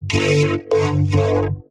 我甚至学会了如何进行实时的vocodex Skype通话：O btw 这是我做的一个声乐录音。
Tag: 140 bpm Electronic Loops Vocal Loops 295.49 KB wav Key : G